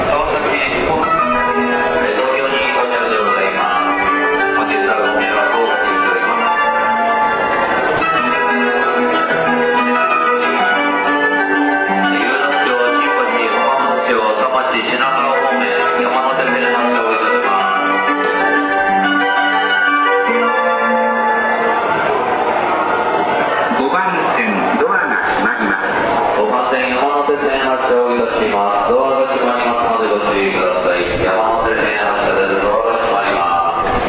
日本の玄関口ですが、メロディーは特に変わっているものでもありません。
発車5音色c --